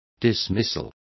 Complete with pronunciation of the translation of dismissals.